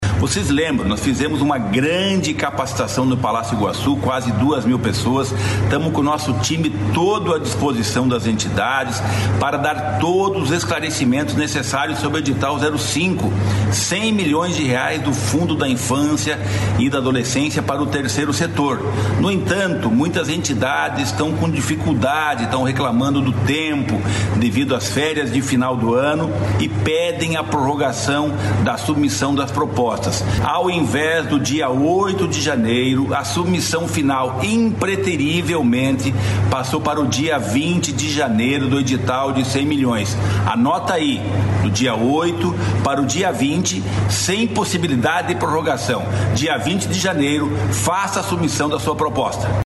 O prazo anterior era até 8 de janeiro e foi prorrogado após solicitação de muitas entidades, como explica o secretário Rogério Carboni.